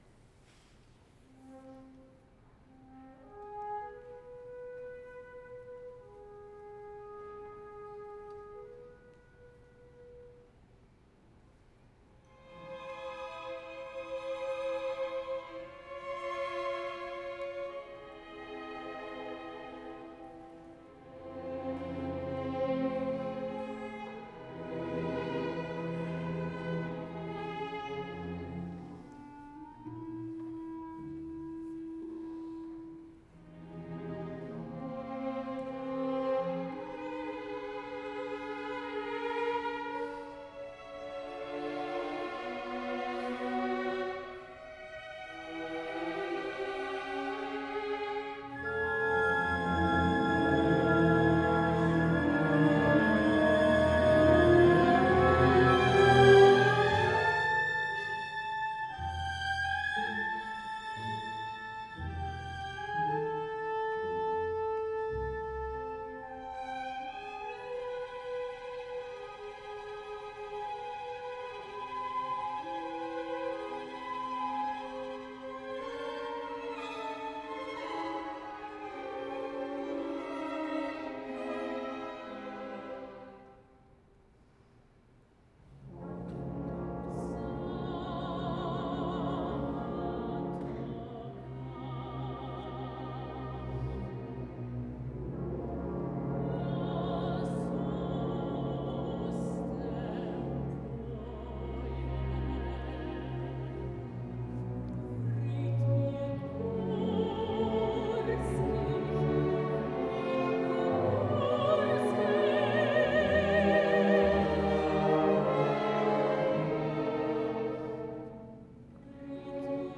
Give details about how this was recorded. Boston performance